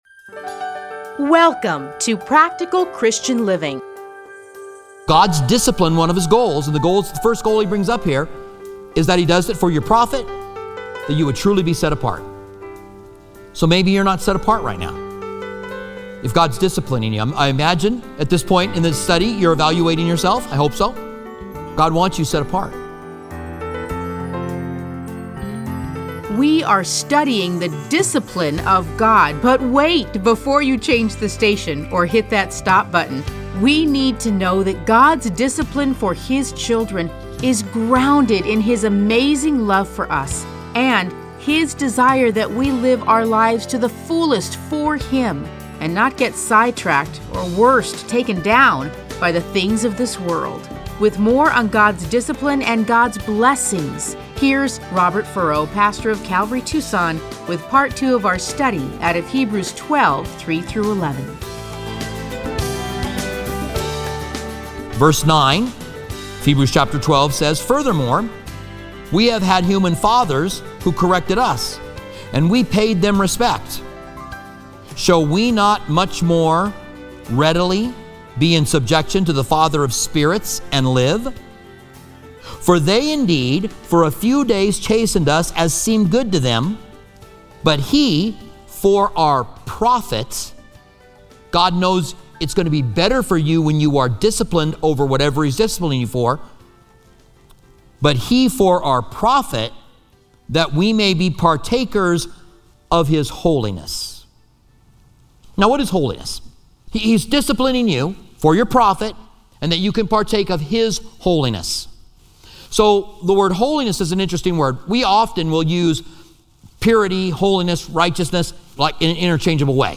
Listen to a teaching from Hebrews 12:3-11.